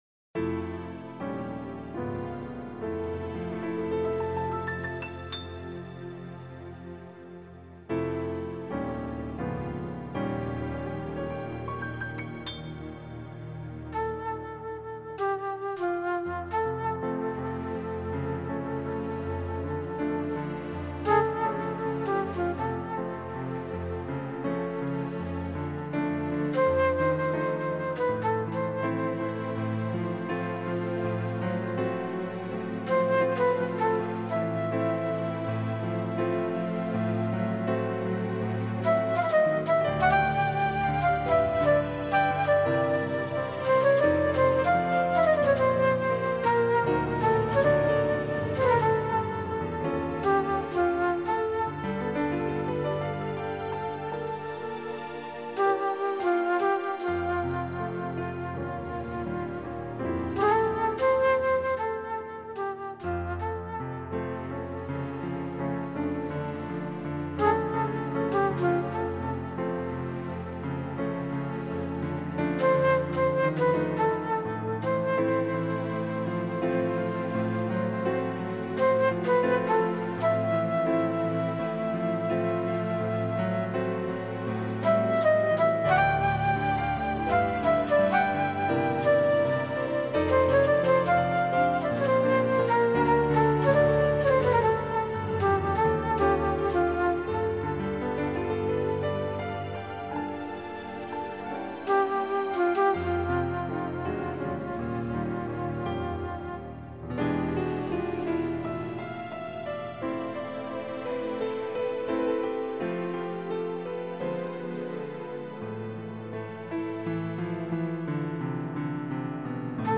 CD Instrumental